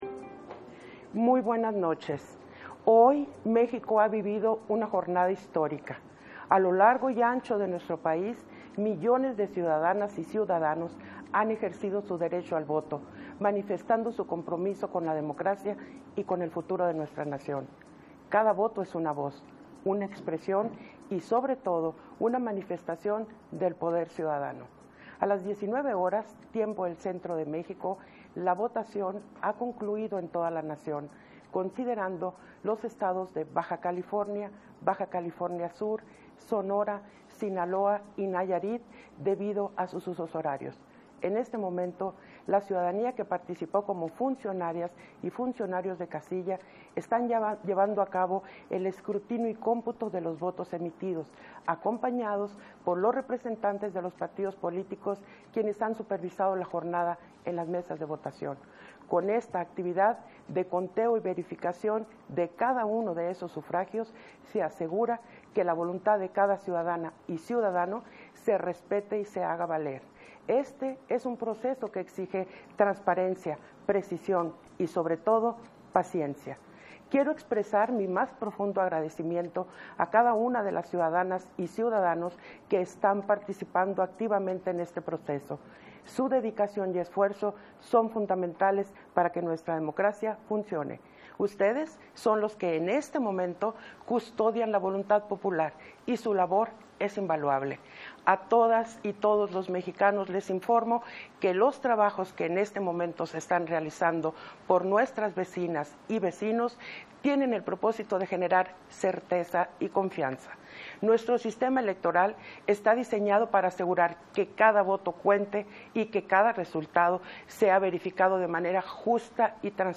Segundo mensaje en cadena nacional de la Consejera Presidenta, Guadalupe Taddei